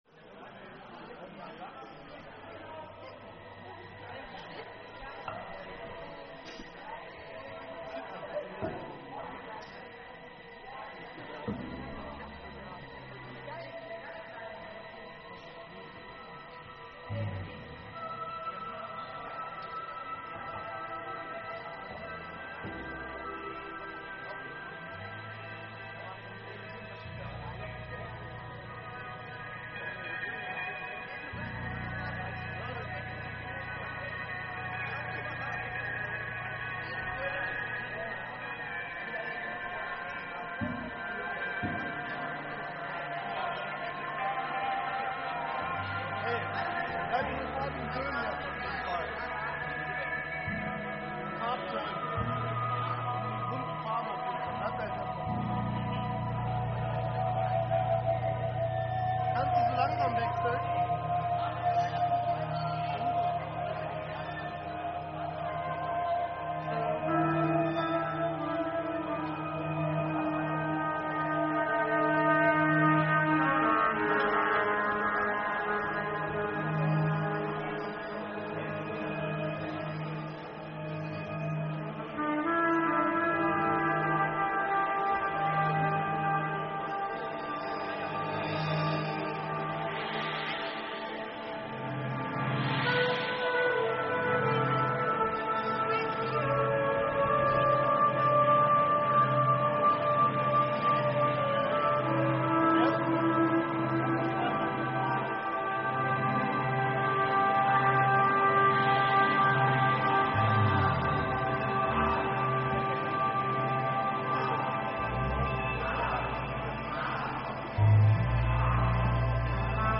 Forum, Grevenbroich
keyb